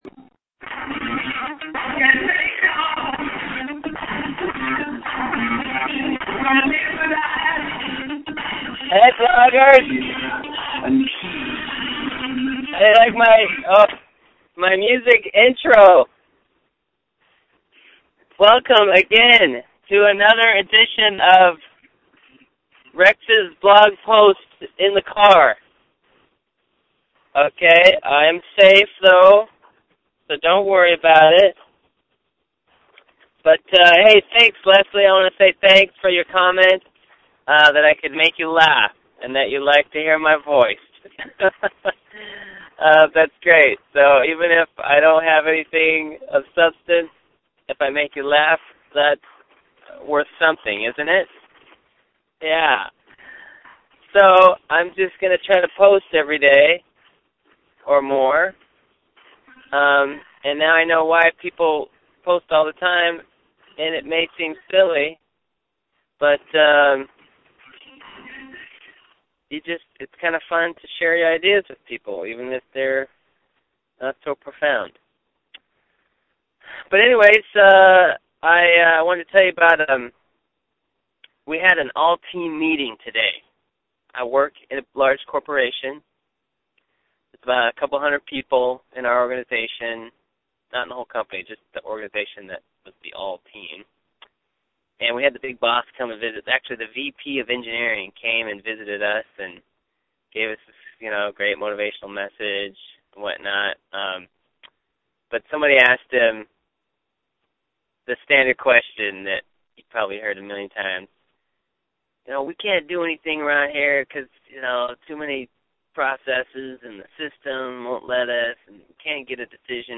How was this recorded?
The car was parked this time. And I know, the music sounds horrible. I guess you can’t get high def sound through the cell phone air waves.